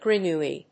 /grʌˈnui(米国英語), grʌˈnu:i:(英国英語)/